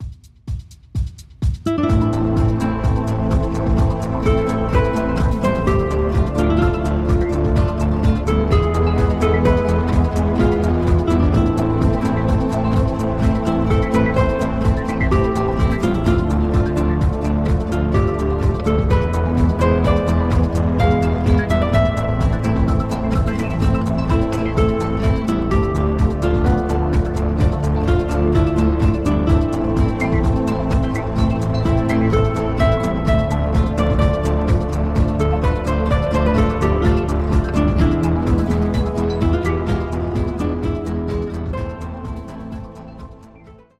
CHILLOUT LOUNGE MUSIC